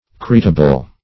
creatable.mp3